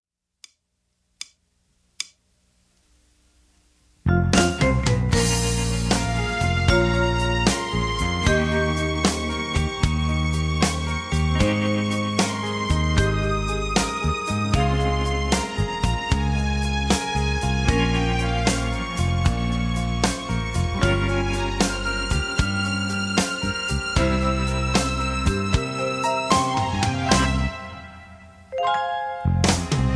backing tracks
country rock